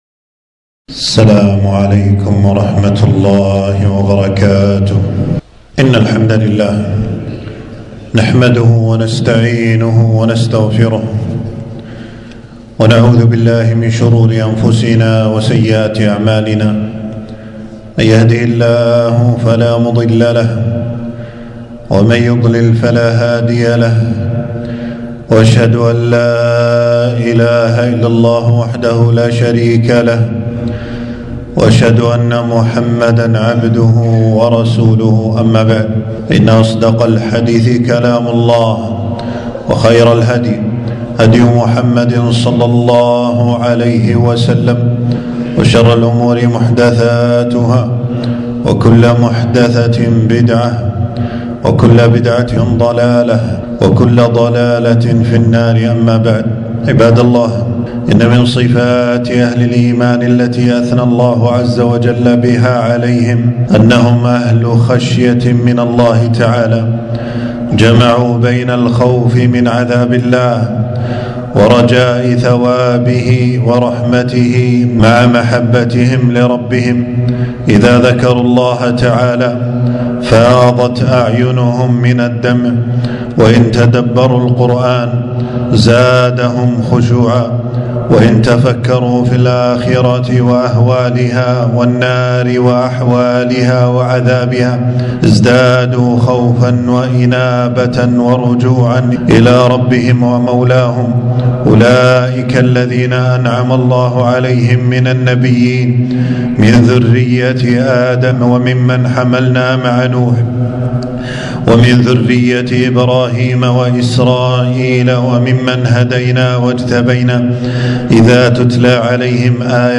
تنزيل تنزيل التفريغ خطبة بعنوان: إن الذين هم من خشية ربهم مشفقون.
حفظه الله تعالى المكان: خطبة في يوم 8 شعبان 1446هـ في مسجد السعيدي بالجهرا.